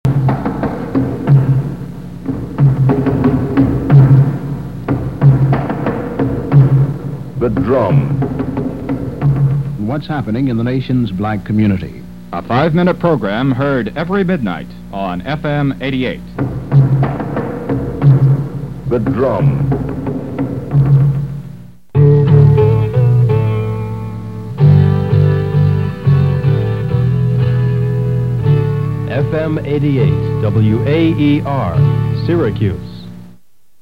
A station promo (I voiced the part about "a five-minute program") and a station ID from the summer of 1970.